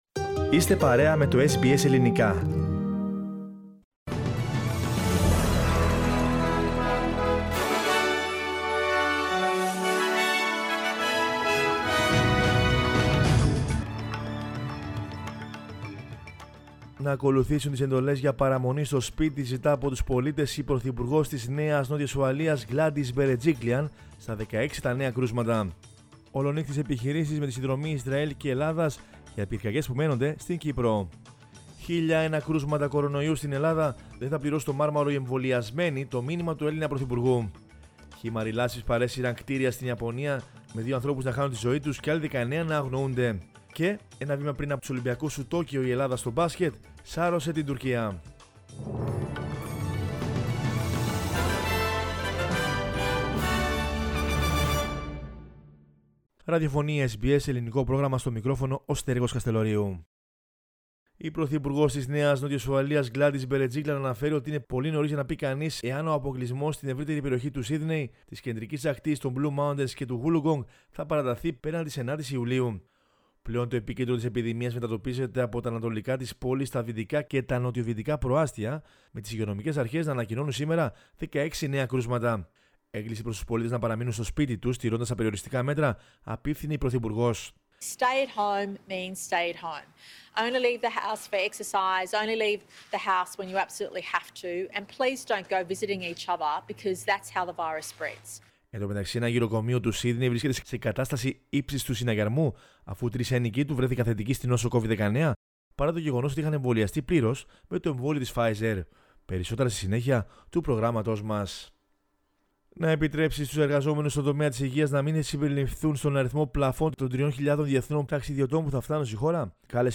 News in Greek from Australia, Greece, Cyprus and the world is the news bulletin of Sunday 4 July 2021.